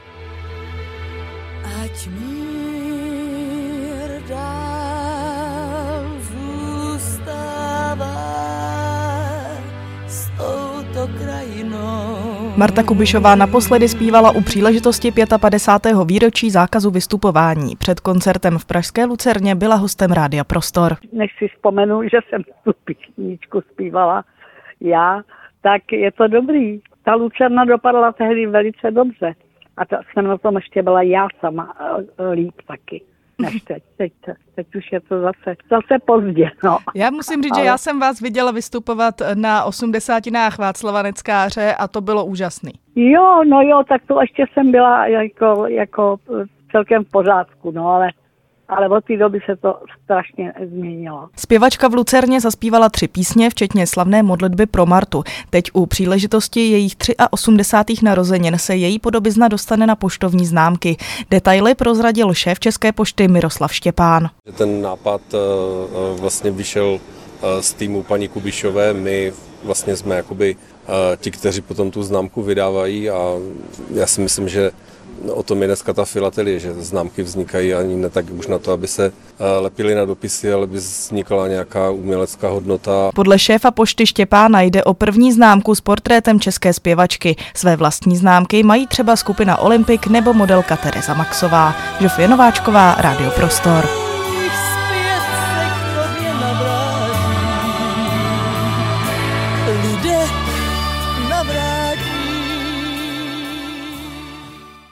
Reportáž o známkách s Martou Kubišovou